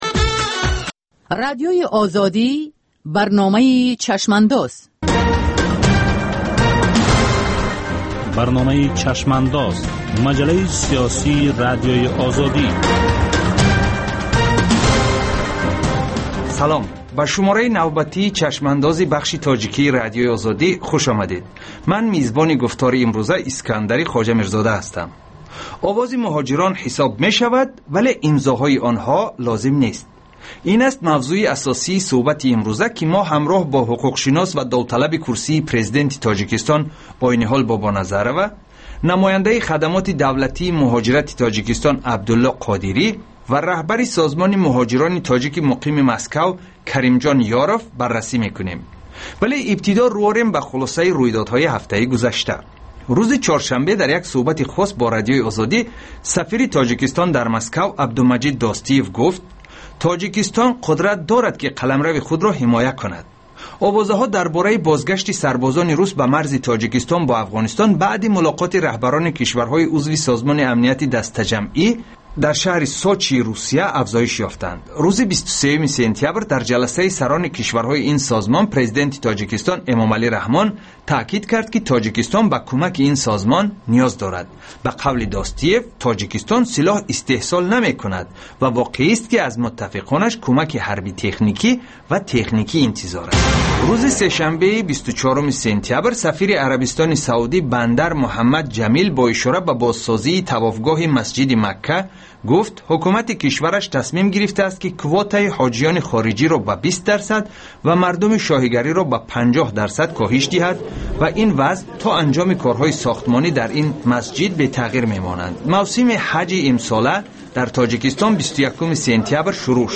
Ҷусторе дар рӯйдодҳои сиёсии ҷаҳон, минтақа ва Тоҷикистон дар як ҳафтаи гузашта. Мусоҳиба бо таҳлилгарони умури сиёсӣ.